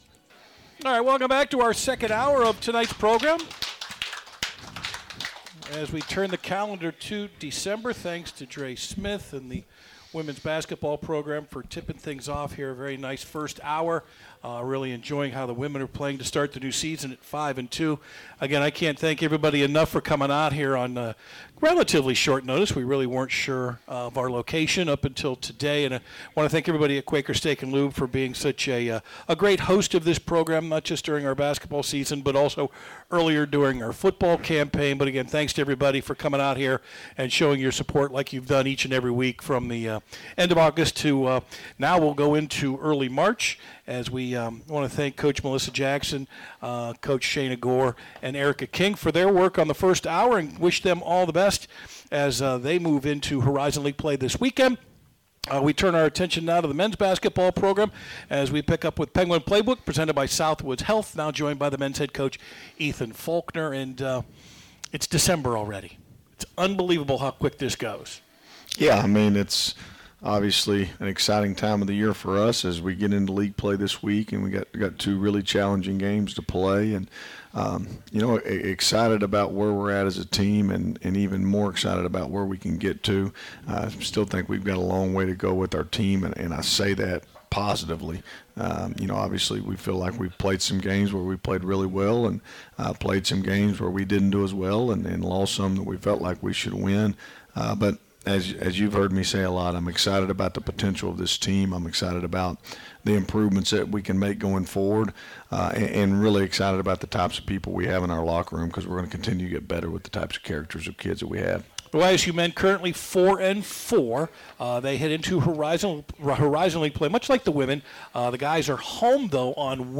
Postgame Interview